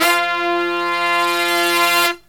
LONG HIT04-R.wav